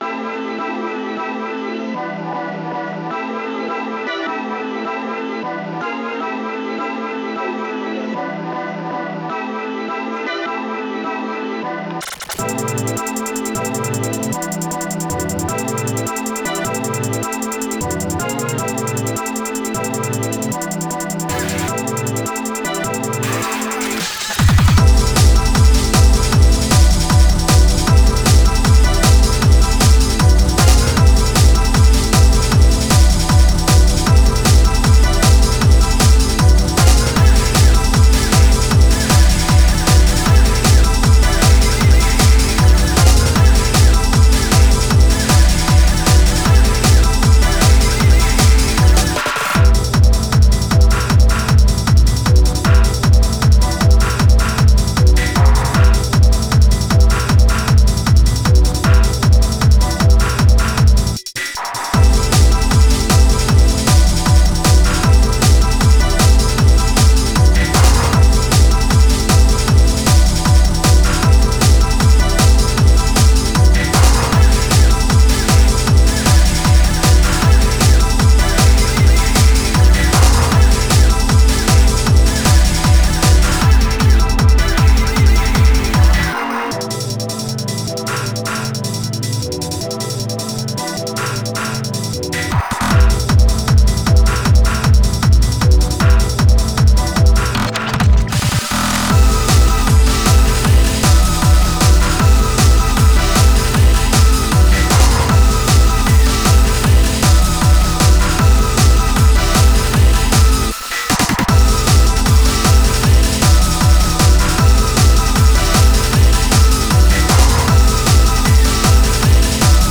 a short digital hardcore track about the surge of